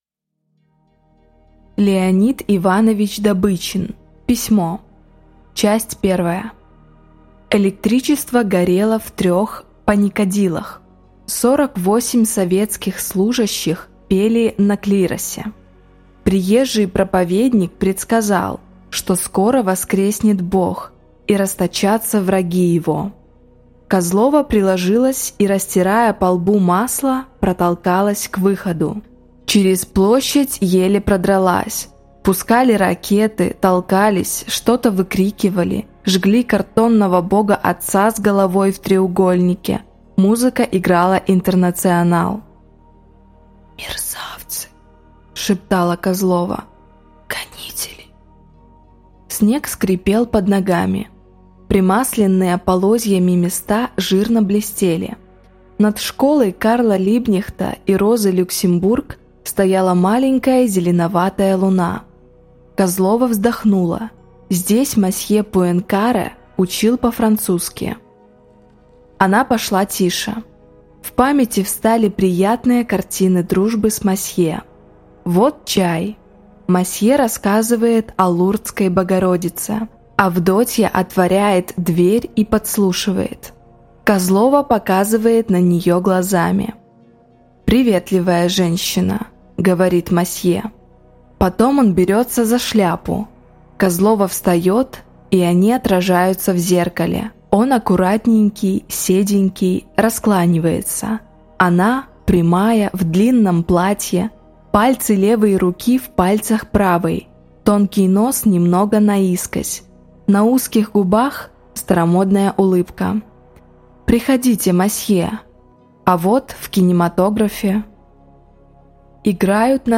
Аудиокнига Письмо | Библиотека аудиокниг